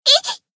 贡献 ） 分类:蔚蓝档案语音 协议:Copyright 您不可以覆盖此文件。
BA_V_Mutsuki_Newyear_Battle_Damage_1.ogg